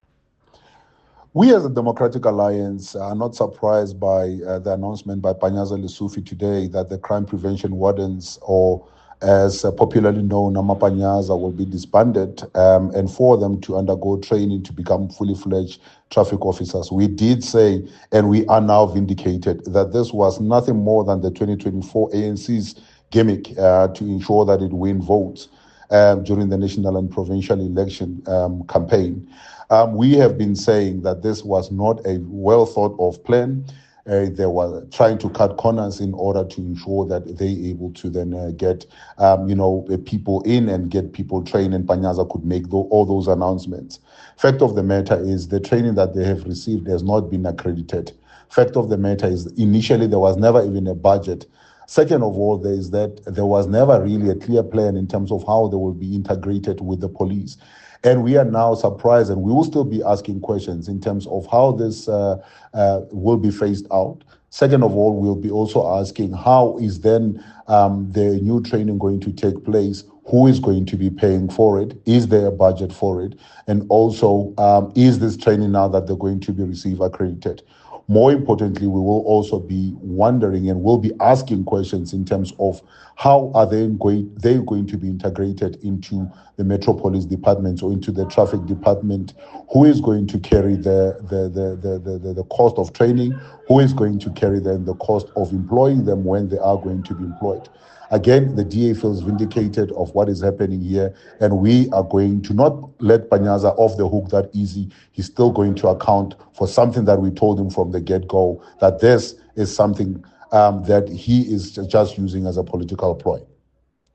soundbite by Solly Msimanga MPL, DA Gauteng Leader for the Official Opposition